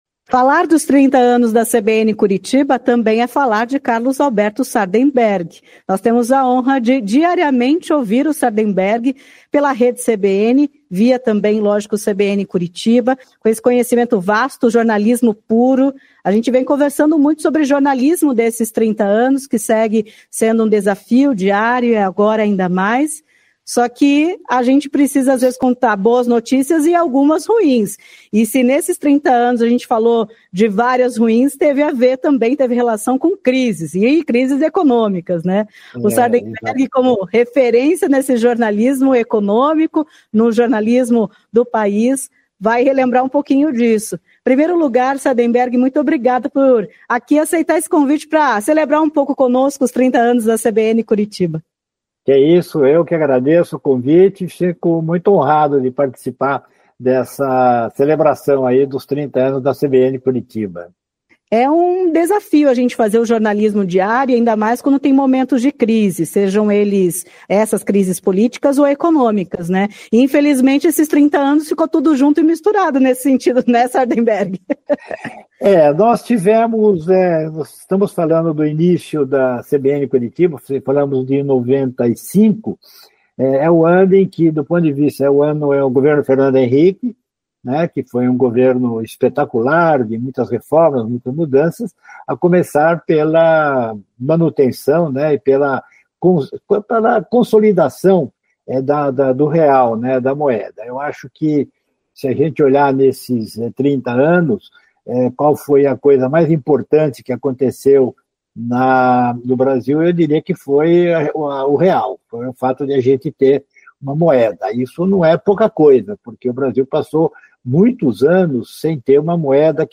Carlos Alberto Sardenberg comenta evolução do radiojornalismo nos últimos 30 anos em entrevista à CBN Curitiba